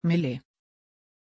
Pronunciation of Millie
pronunciation-millie-sv.mp3